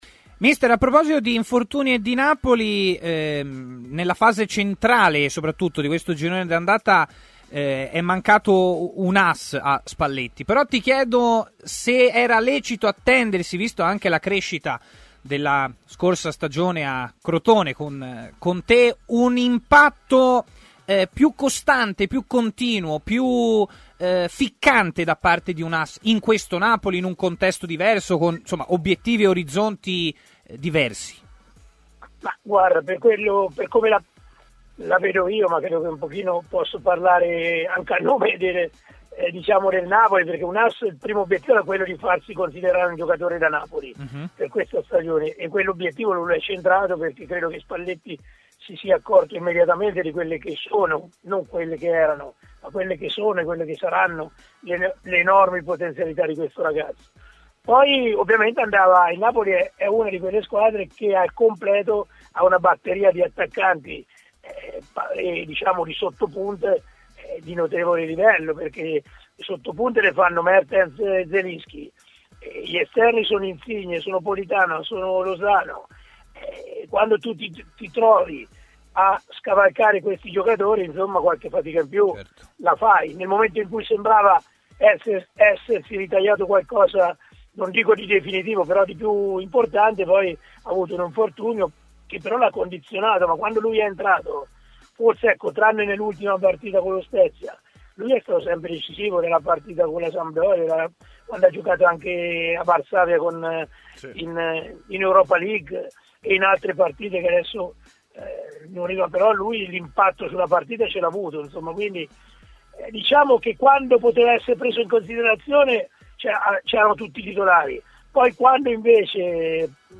L'allenatore Serse Cosmi ha parlato ai microfoni di Tmw Radio: "Le prime dieci partite del Napoli facevano presupporre a qualcosa di incredibile.